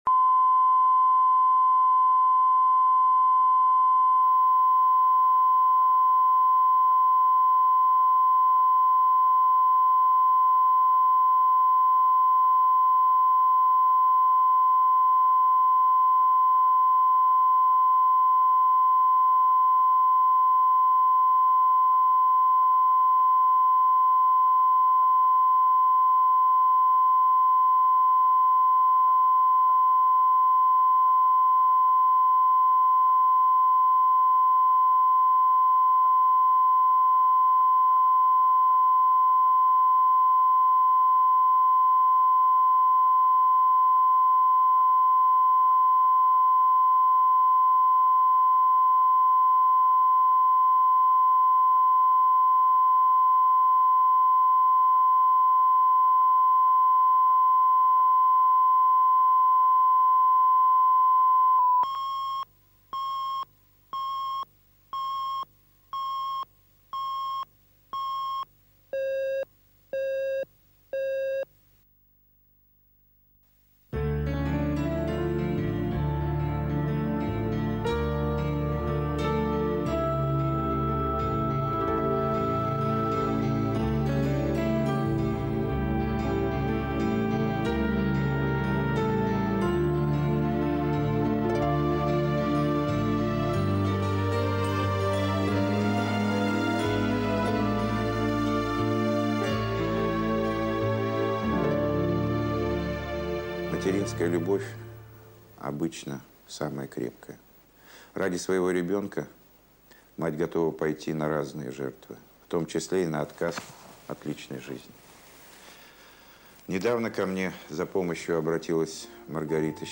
Аудиокнига Две матери | Библиотека аудиокниг